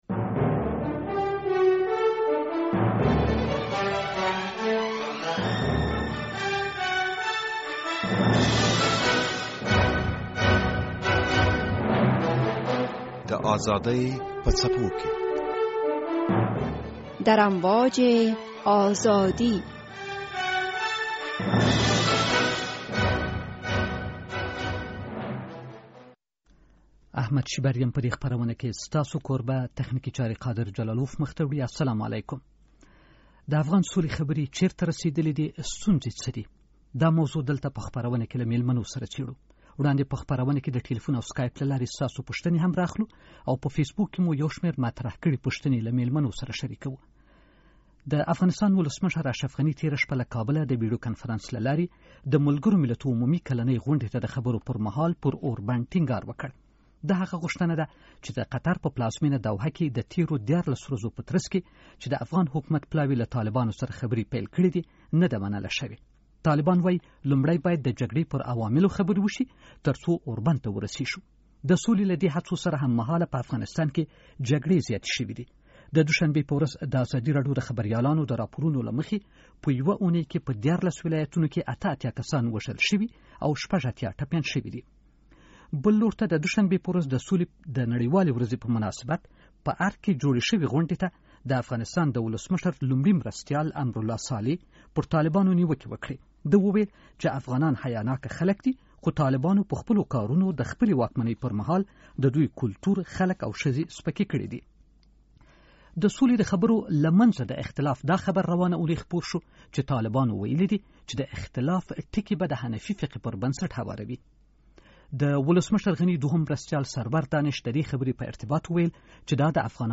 د افغان سولې خبرې په کوم لوري روانې دي او ستونزې څه دي چې په نږدې دوو اوونیو کې د حکومت پلاوي او طالبانو د طرزالعمل خبرې پای ته نه‌دي رسولي. دلته په دې اړه بحث اورېدلی شئ: د ازادۍ په څپو کې